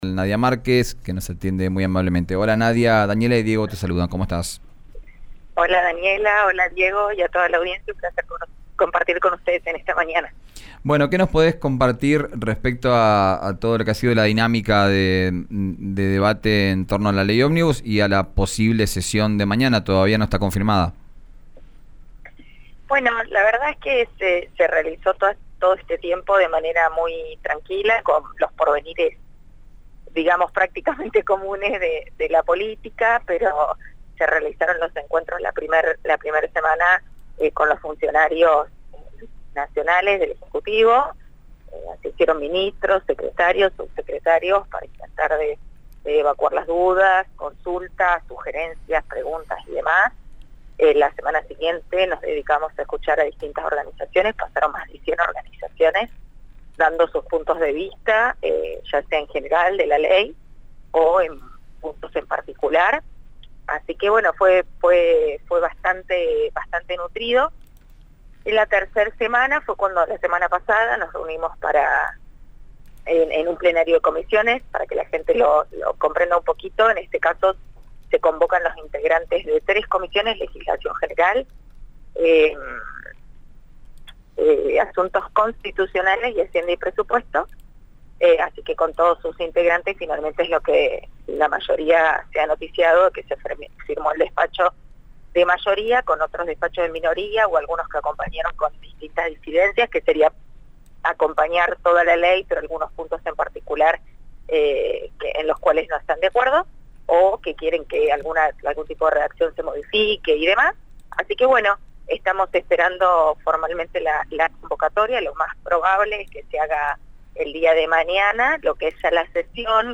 Escuchá a la diputada nacional Nadia Márquez en RÍO NEGRO RADIO: